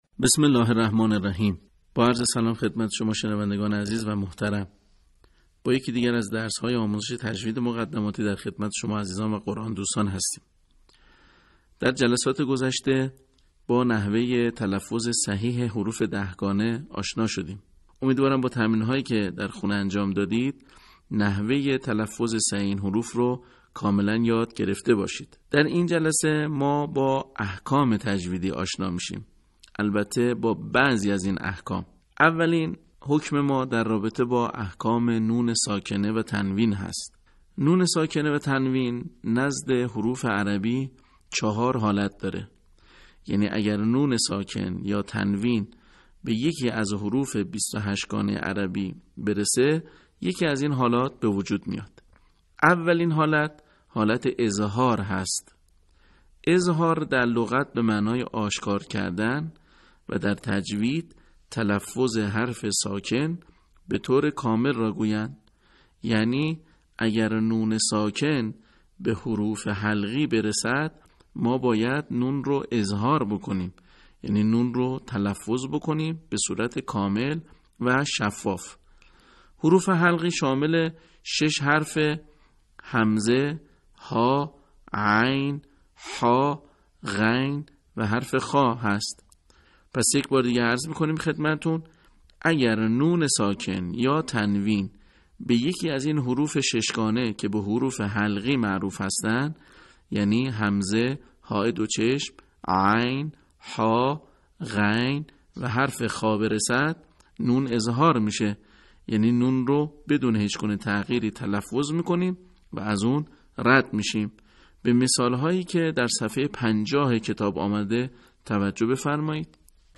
صوت | احکام تجویدی
به همین منظور مجموعه آموزشی شنیداری (صوتی) قرآنی را گردآوری و برای علاقه‌مندان بازنشر می‌کند.